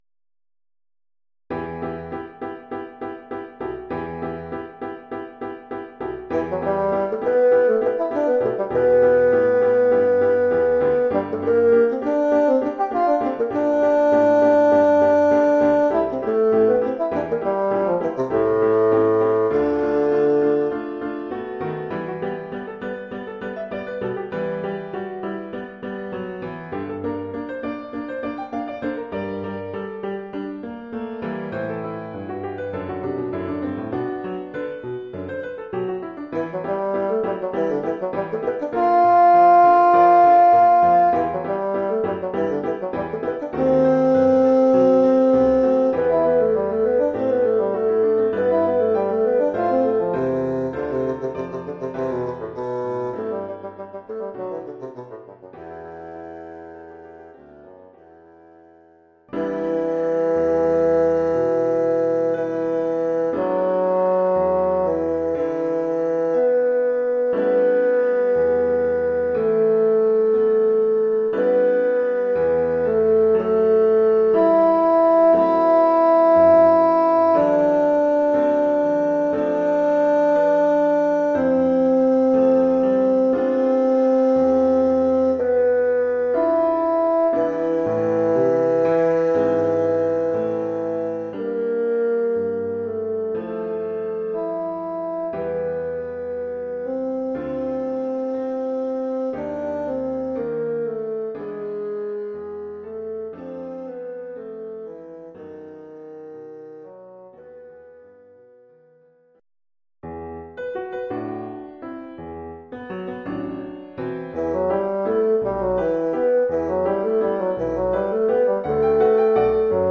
Formule instrumentale : Basson et piano
Oeuvre en trois mouvements,
pour basson et piano.
alternant, de fait, virtuosité et lyrisme.
grâce à une mélodie calme et recueillie.